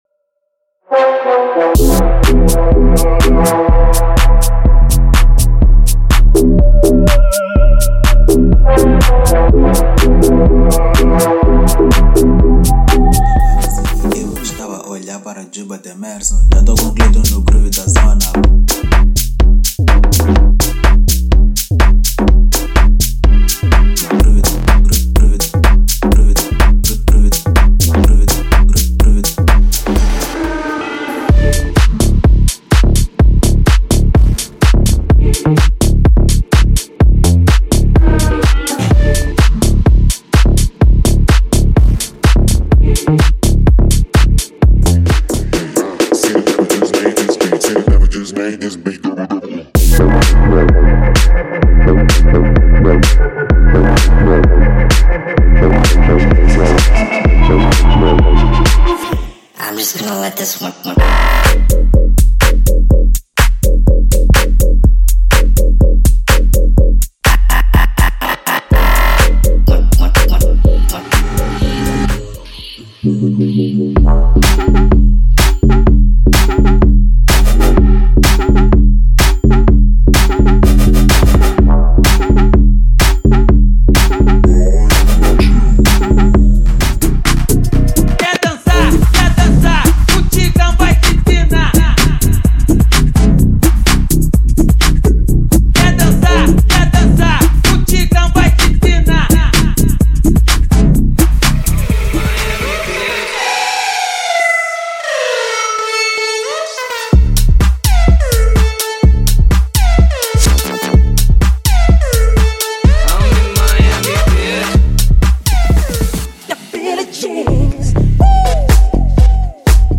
Sem Vinhetas